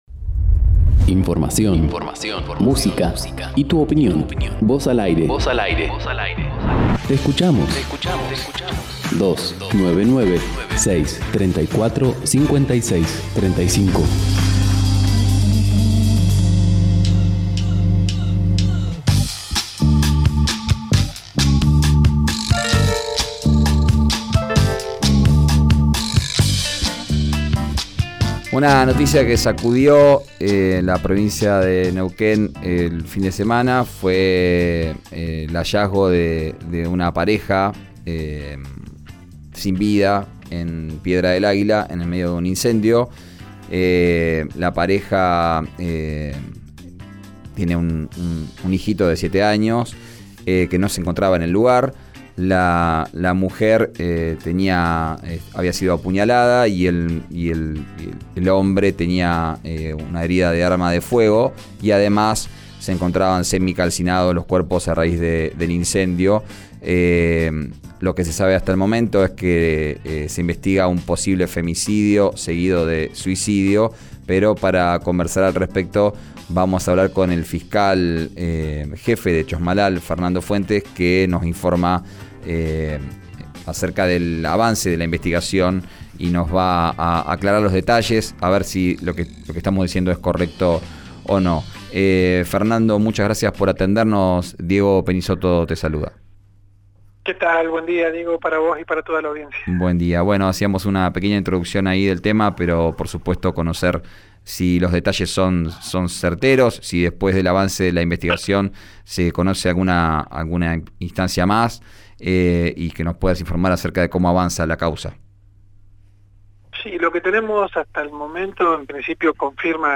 Escuchá al fiscal jefe de Chos Malal, Fernando Fuentes, en RÍO NEGRO RADIO: